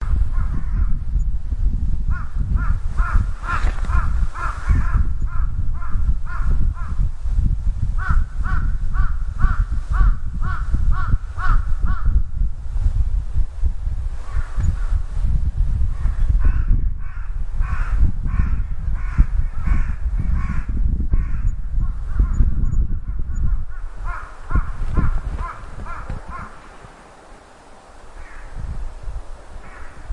乌鸦
描述：录制一些乌鸦发出的声音，同时飞过。MKH40话筒，FP24前置放大器进入R09HR录音机。
标签： 希区柯克的鸟 市的athmosphere 现场录音 乌鸦 乌鸦
声道立体声